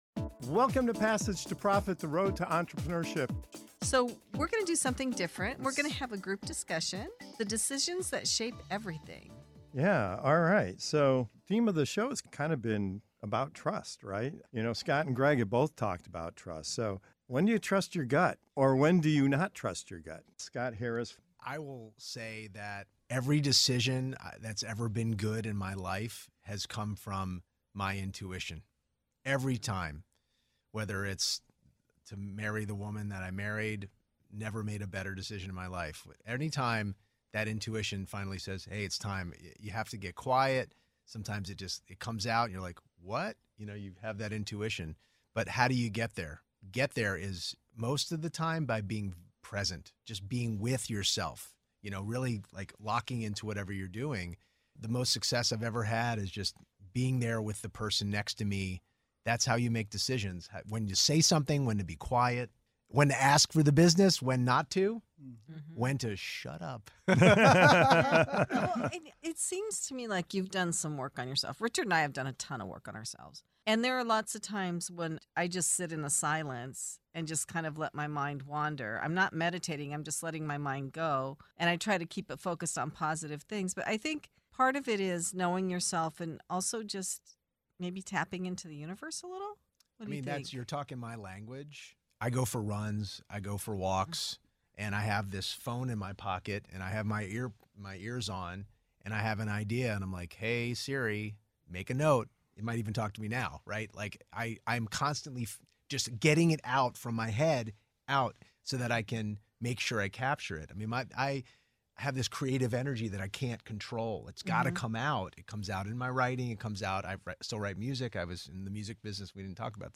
In this candid roundtable, seasoned founders and business leaders break down the real decision-making moments that shape success, from hiring the wrong partner to navigating tough conversations and high-stakes relationships. They reveal why intuition is often built on experience and failure, how being present sharpens your instincts, and why ignoring your gut can lead to costly mistakes.